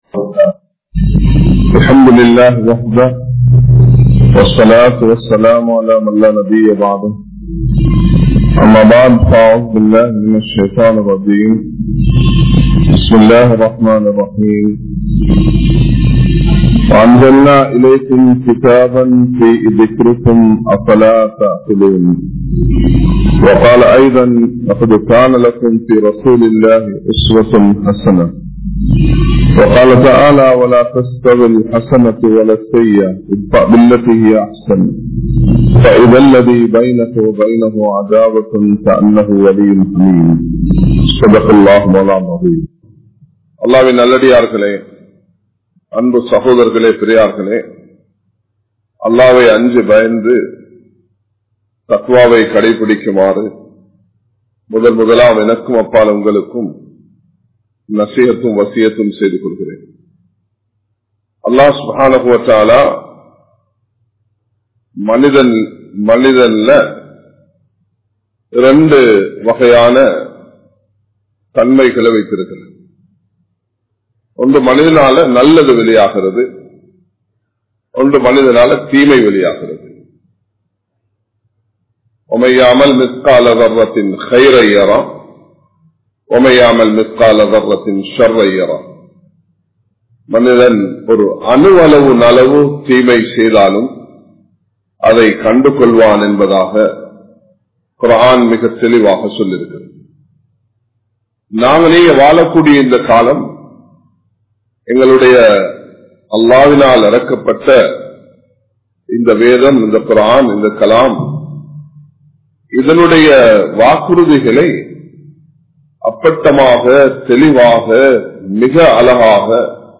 Muslimkal Koalaihal Illai | Audio Bayans | All Ceylon Muslim Youth Community | Addalaichenai
Samman Kottu Jumua Masjith (Red Masjith)